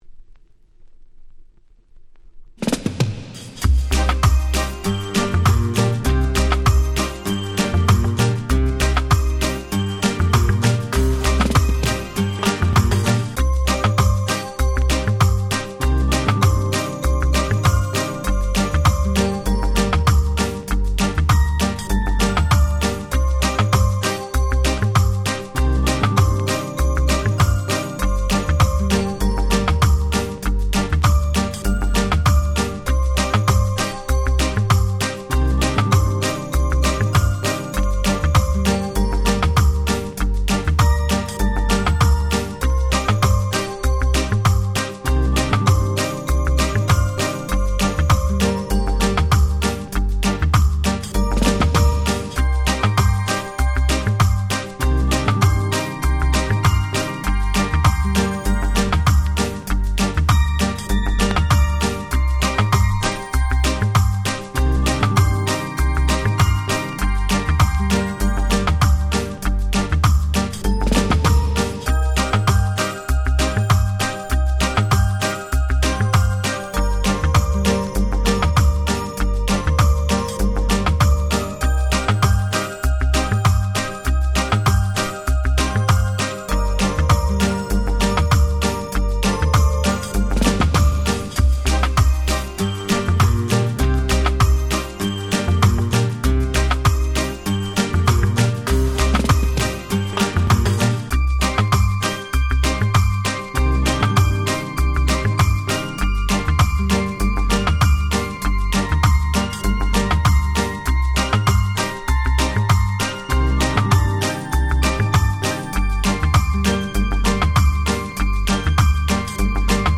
07' Very Nice Break Beats !!